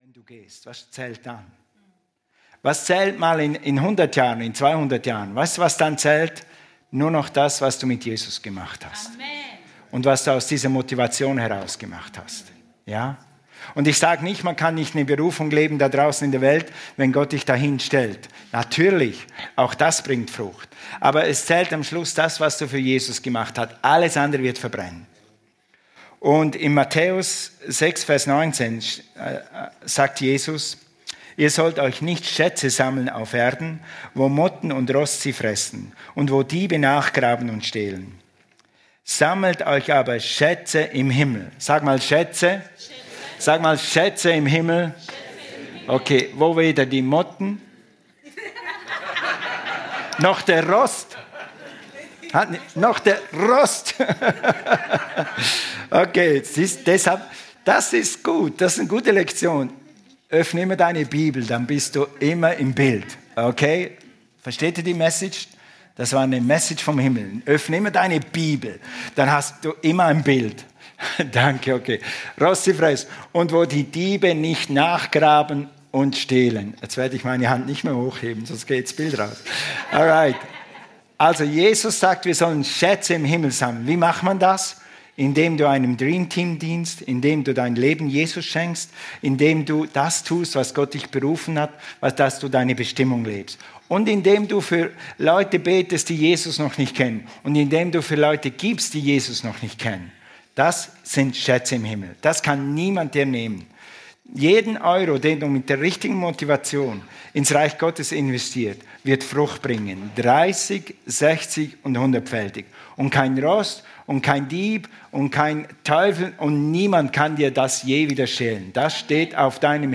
Dein Vermächtnis - Legacy, Teil 1 ~ Predigten vom Sonntag Podcast
Alle Predigten aus den Sonntagsgottesdiensten